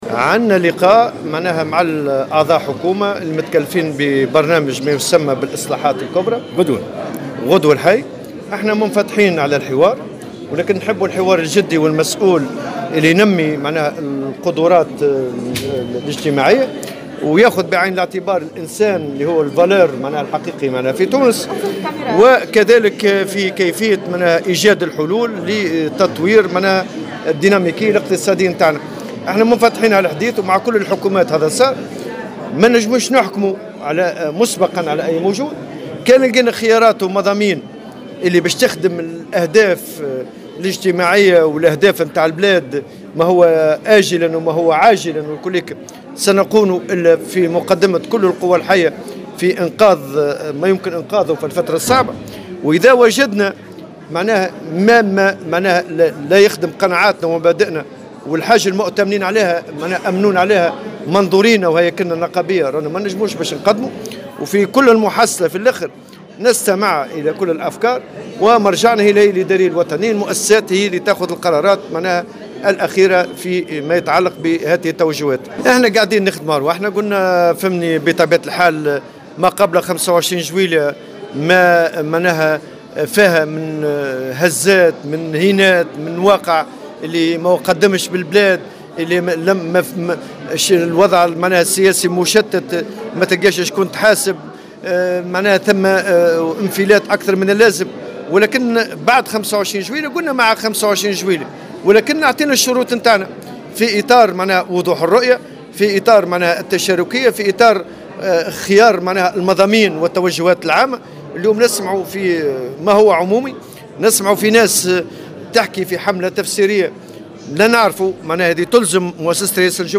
وأضاف في تصريح لـ "الجوهرة أف أم" على هامش حضوره، اليوم الجمعة، لافتتاح تظاهرة أيام المؤسسة، في سوسة، أن الاتحاد لن يسمح بالتراجع عن الاتفاقيات الممضاة مع الطرف الحكومي أو عن المفاوضات الاجتماعية التي قطعت شوطا كبيرا في القطاع الخاص، مشددا على أن الاتحاد ليس مسؤولا عن تردي الوضع السياسي والاقتصادي في البلاد.